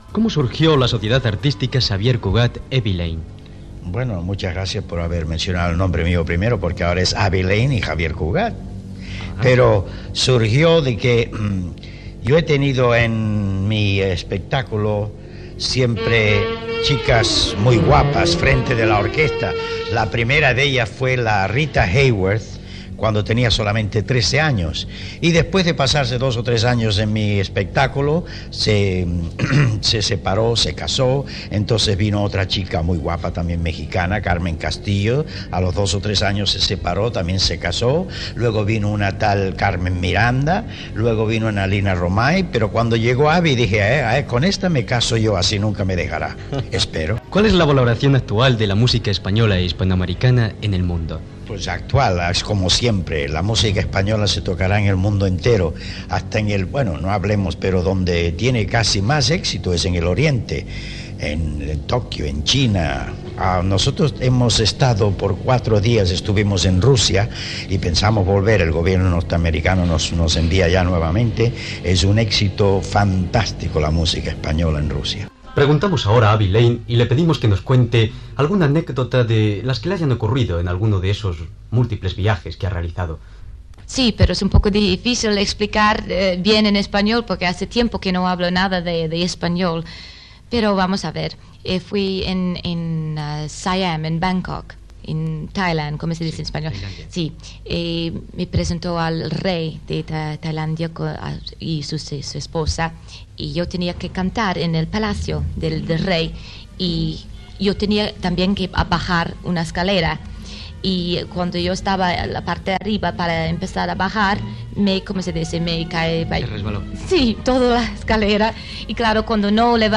El músic Xavier Cugat, a Madrid, parla de la seva relació amb la cantant Abbe Lane i de la música espanyola i hispanoamericana.
Entreteniment